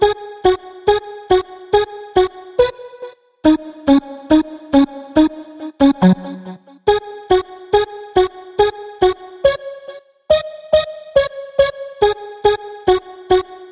天使之声合成器
描述：四部曲Chill Out旋律，抱歉不知道是什么调，140bpm
Tag: 140 bpm Chill Out Loops Synth Loops 2.31 MB wav Key : Unknown